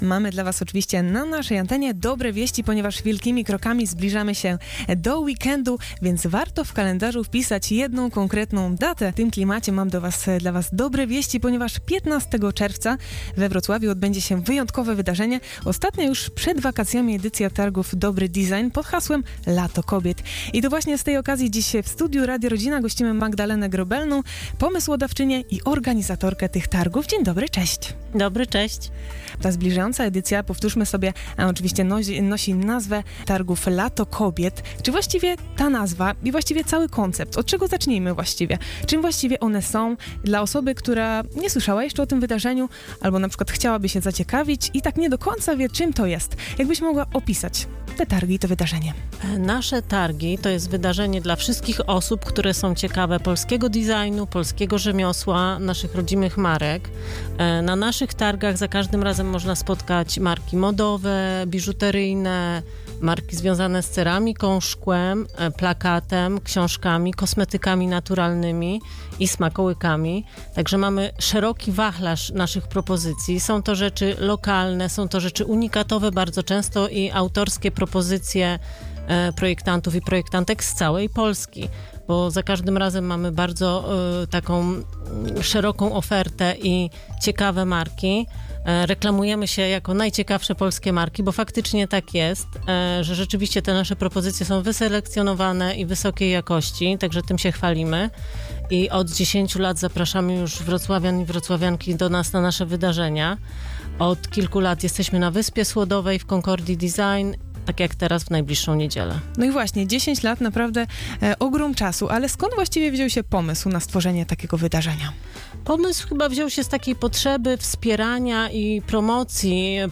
Pełnej rozmowy